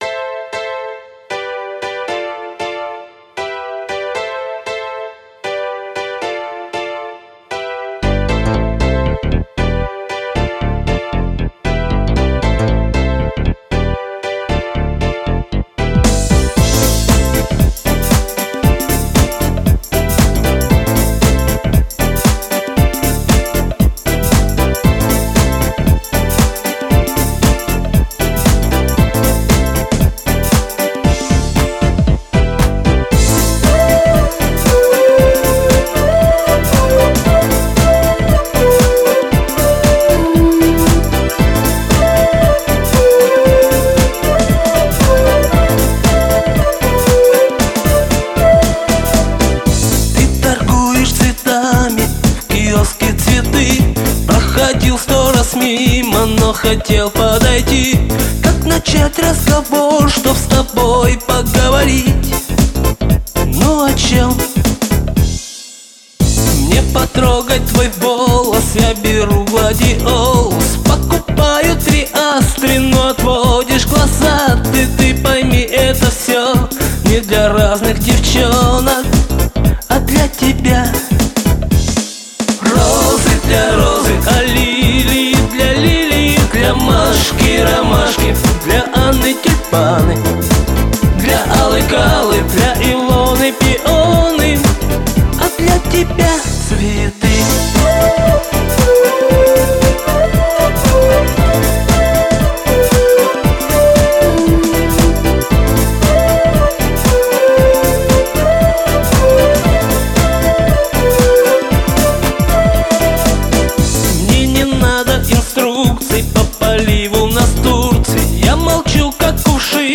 Восстановлено из цифровых архивов.